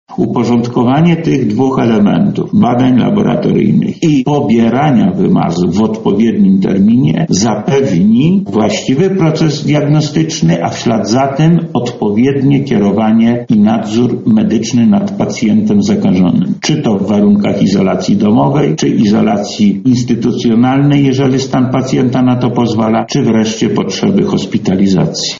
– mówi Lech Sprawka, Wojewoda Lubelski.
– dodaje Sprawka.